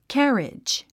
発音
kǽridʒ　キャァリッジ
carriage.mp3